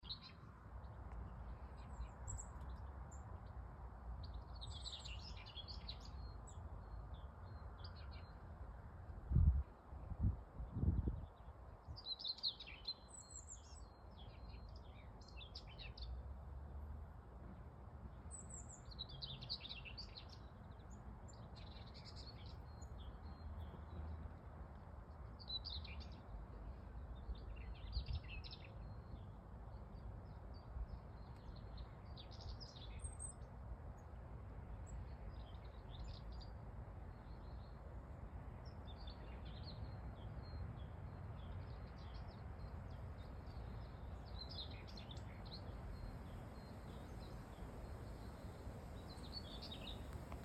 Птицы -> Дроздовые -> 1
каменка, Oenanthe oenanthe
СтатусПоёт